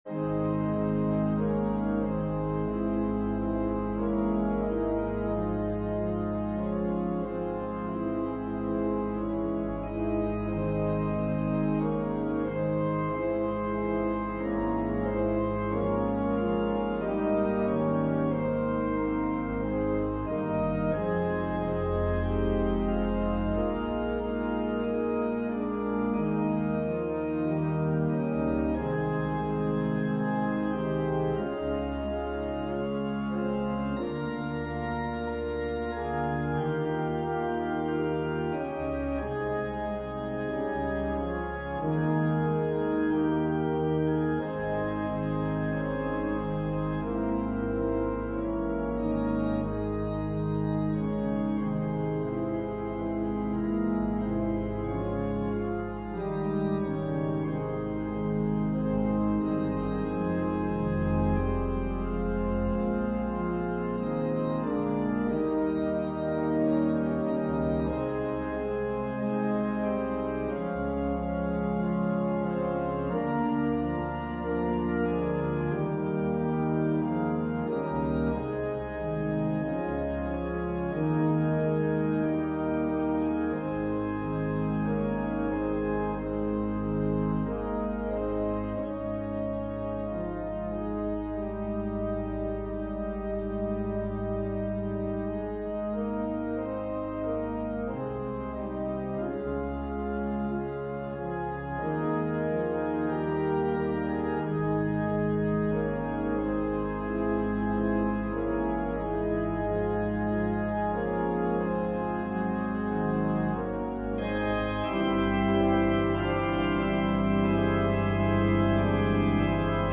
Organ/Organ Accompaniment
An organ solo rendition of Mack Wilberg's choral arrangement.